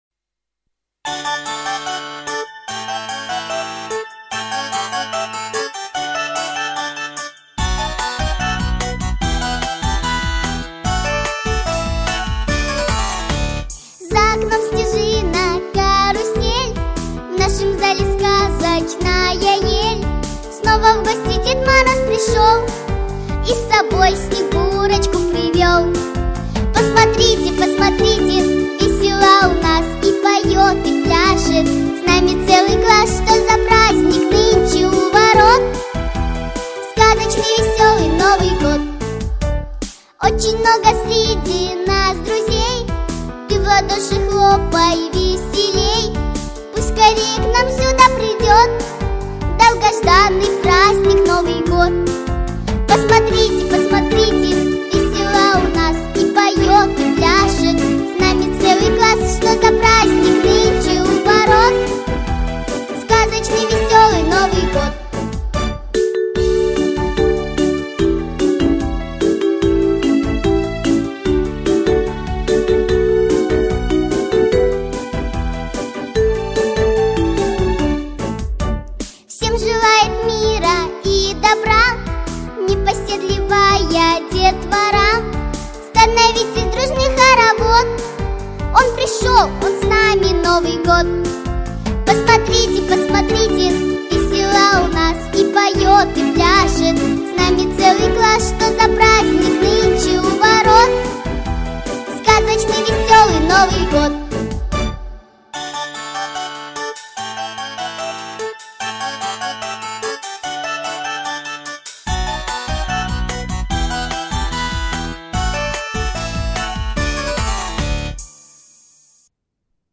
С Новым годом!!! Новогодняя песня из музыкальной школы нашего города (авторы и исполнитель).